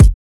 Kick (Gone Be).wav